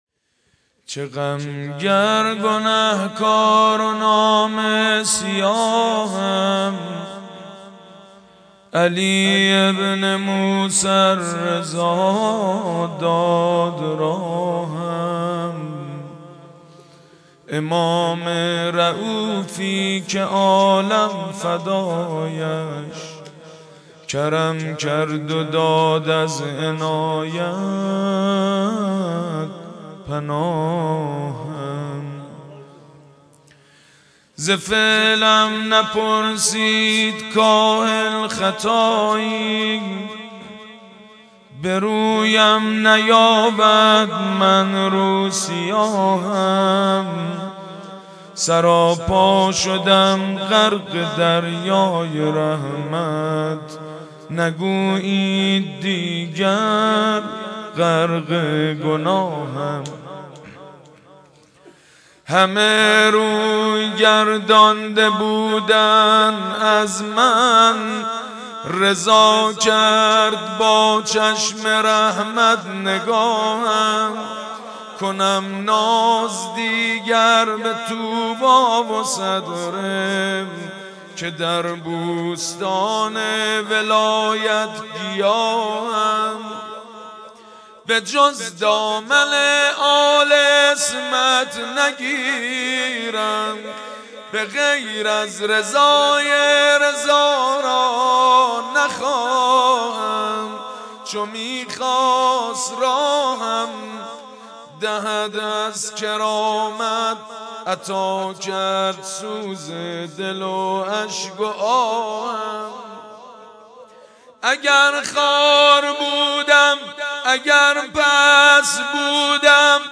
چه غم گر گنهکار و نامه سیاهم | مناجات با حضرت امام رضا علیه السلام
حاج سید مجید بنی فاطمه
هیات ریحانه الحسین